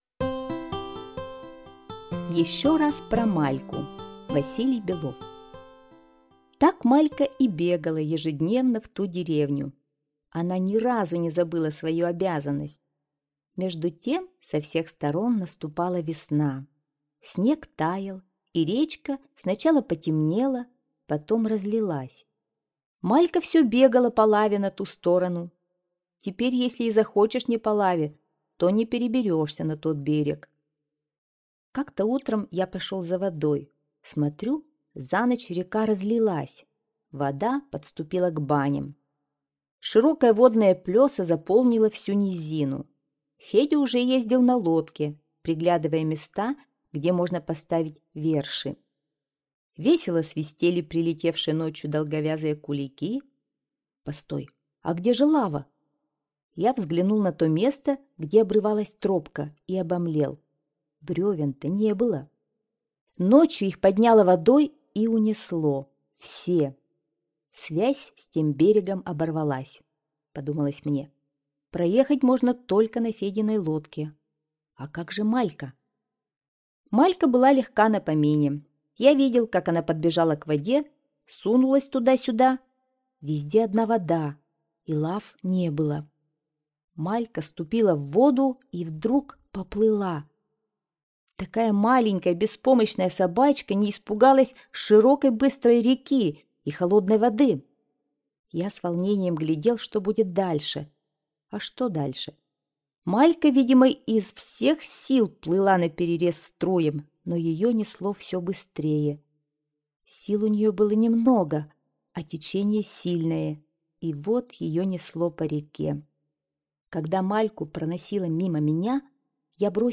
Аудиорассказ «Еще про Мальку» – Белов В.И.
Очень хорошо читает
Нам очень понравилось эта сказка она очень красиво читает